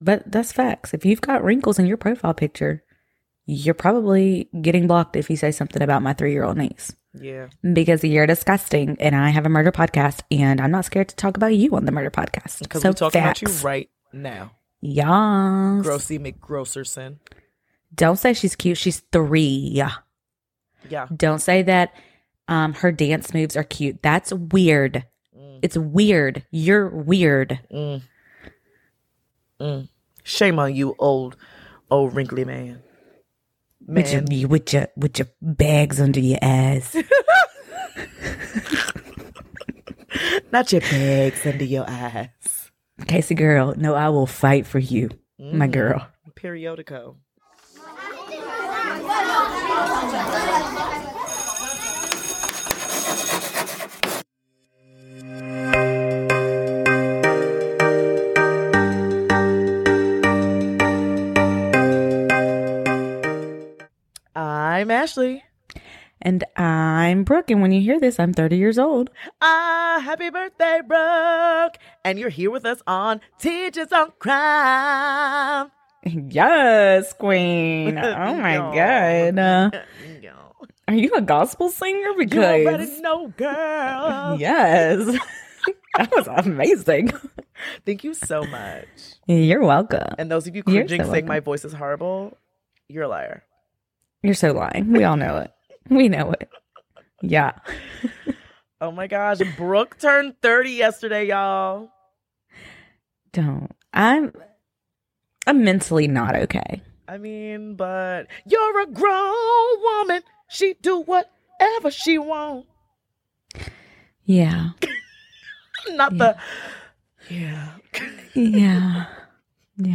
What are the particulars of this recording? She isn't sure what her microphone did, but she wants you to know we work weekly at giving you the best quality possible.